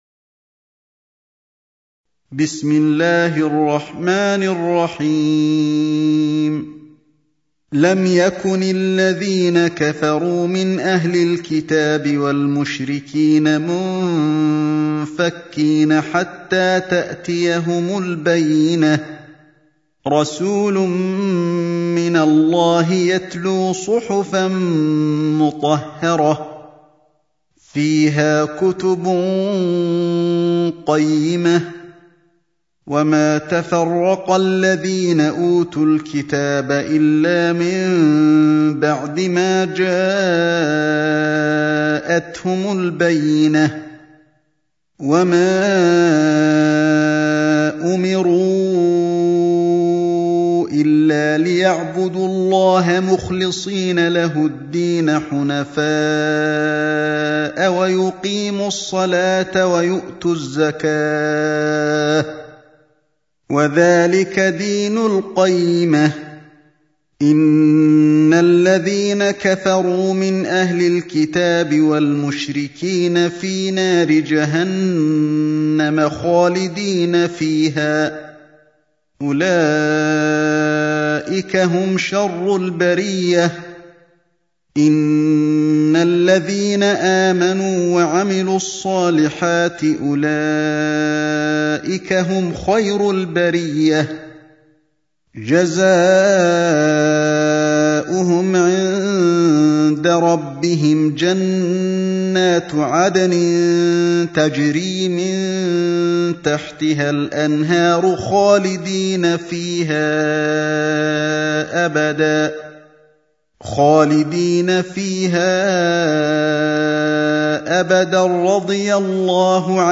سورة البينة | القارئ علي الحذيفي